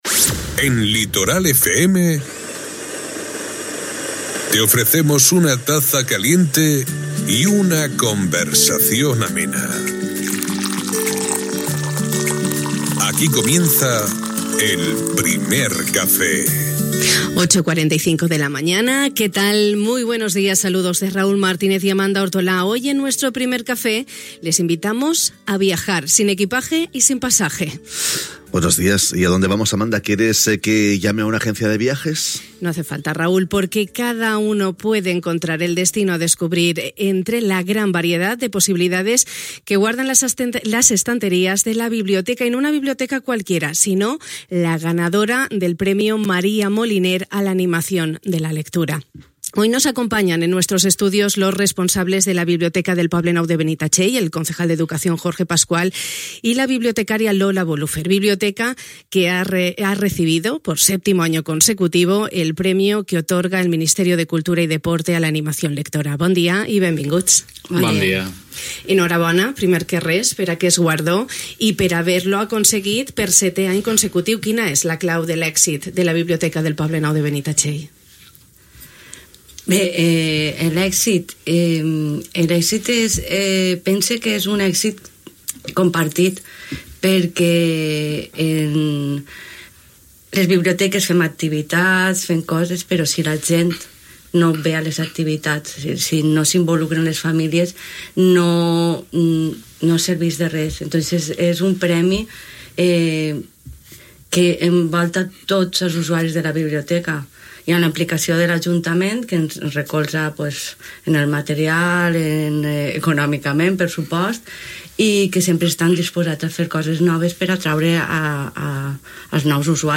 Hui, a Radio Litoral hem compartit el nostre Primer Café, amb els responsables de la Biblioteca del Poble Nou de Benitatxell.